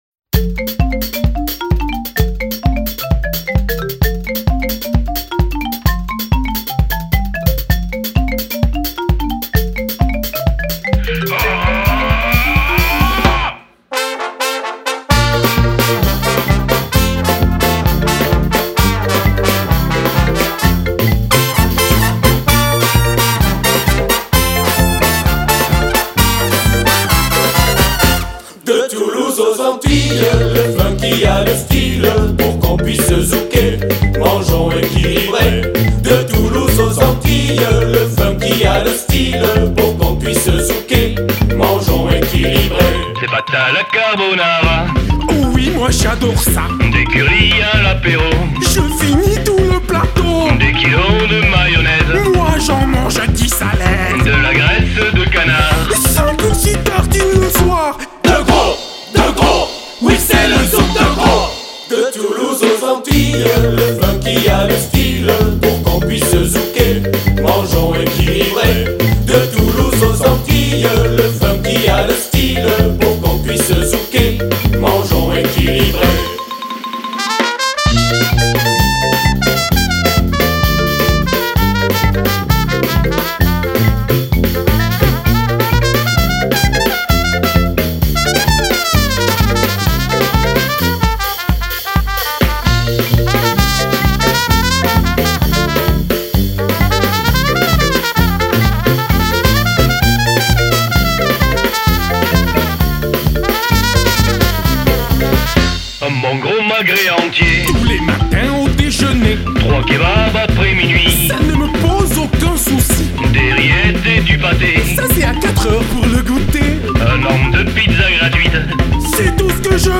FANFARE FESTIVE